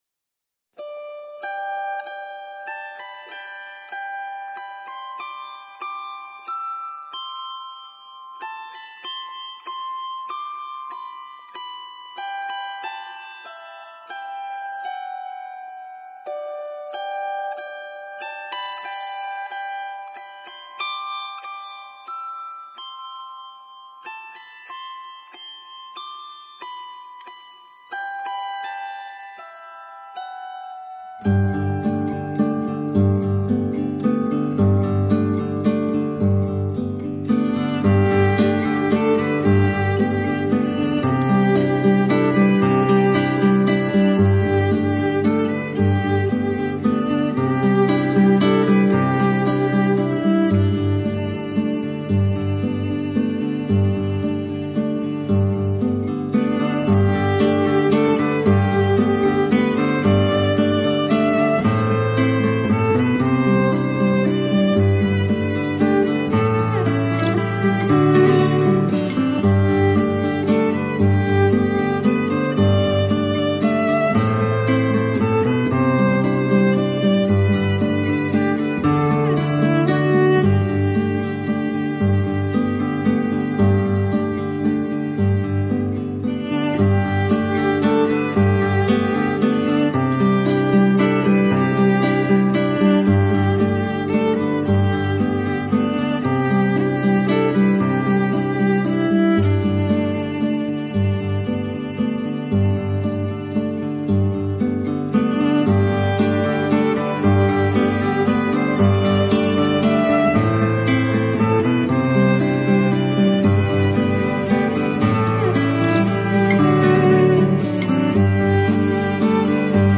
【吉他独奏专辑】
音乐类型：New Age